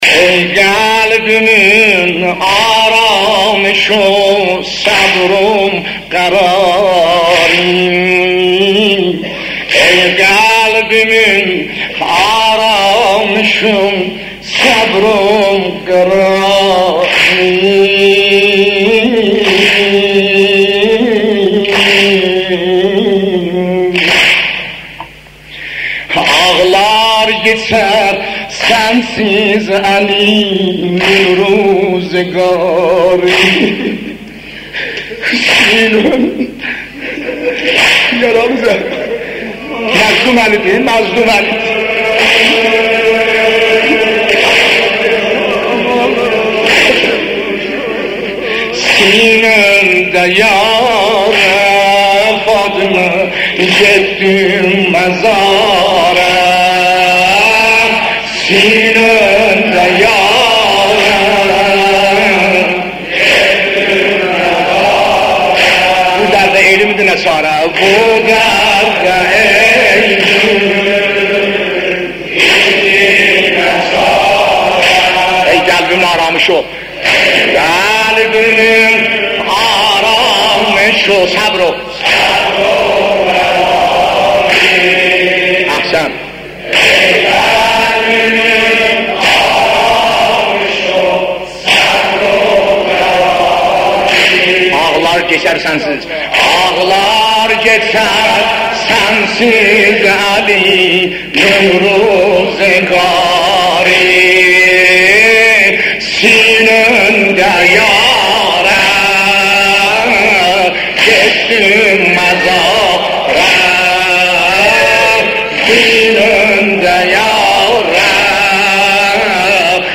مداحی آذری نوحه ترکی
ایام فاطمیه